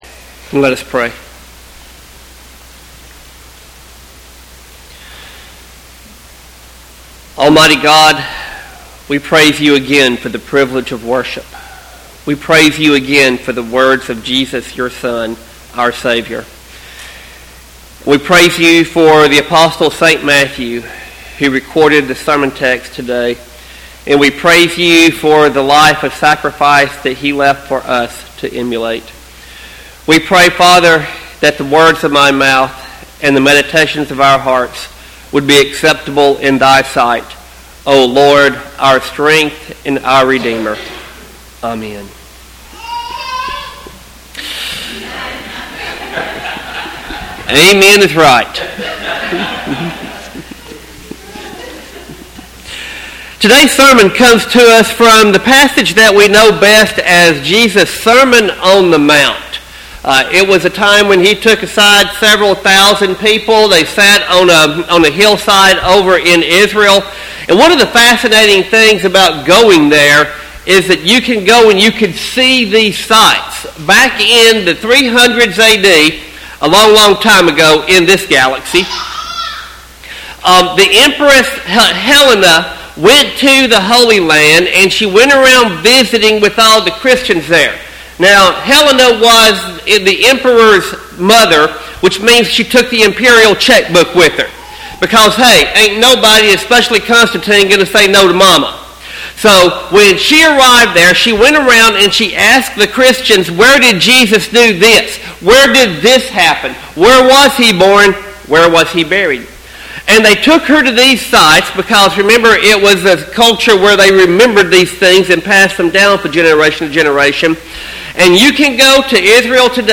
Sermon text: Matthew 5:17-32.